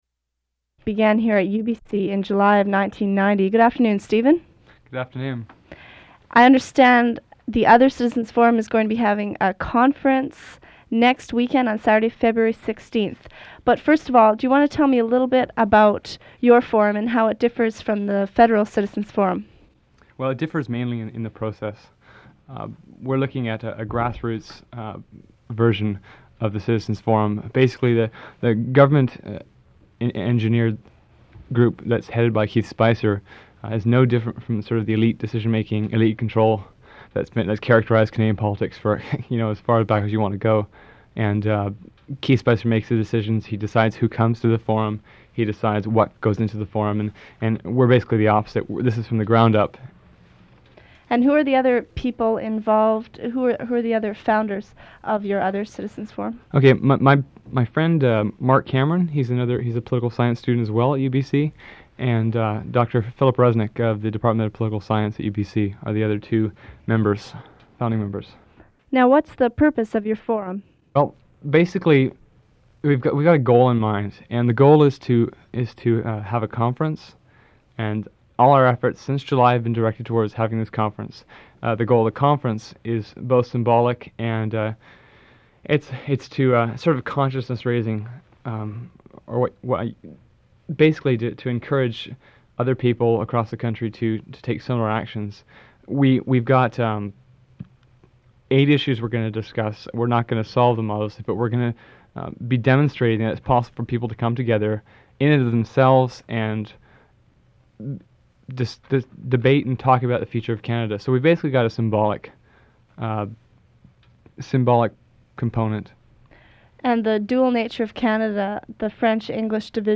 Recording of an interview